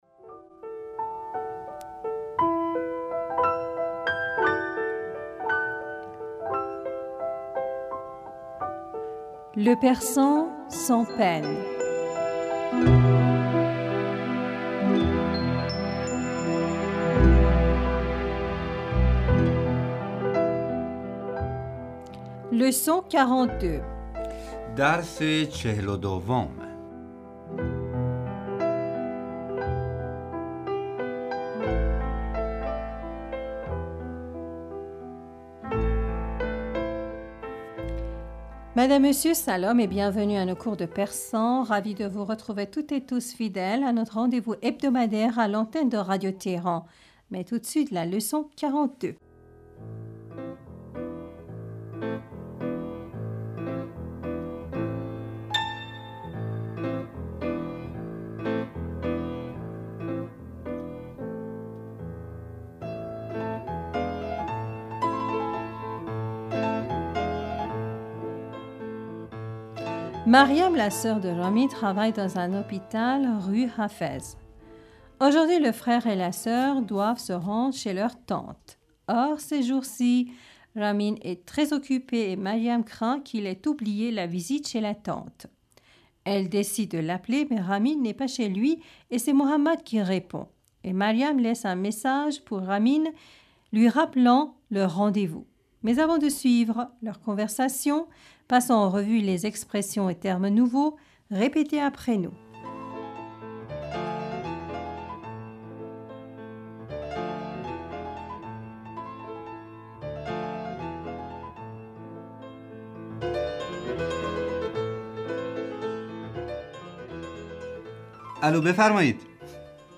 Répétez après nous.
Ecoutons à présent la conversation de Maryam et Mohammad au téléphone.